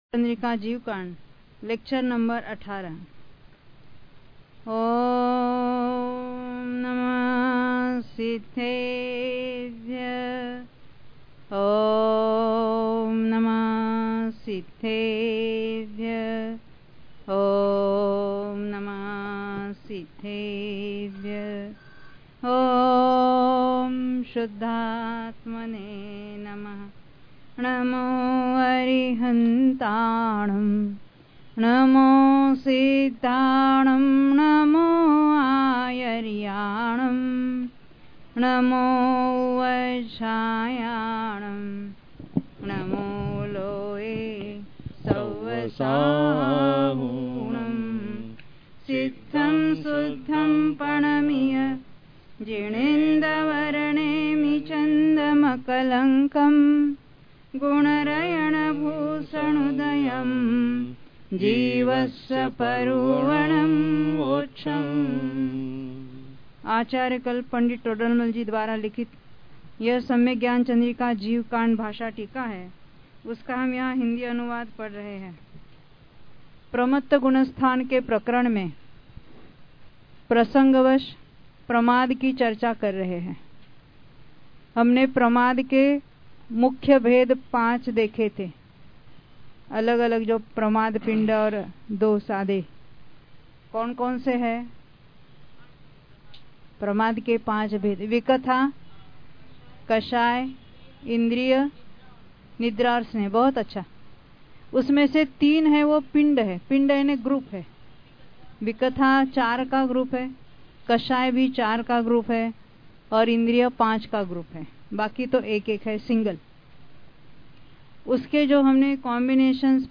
Pravachan